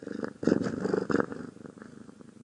purr1.ogg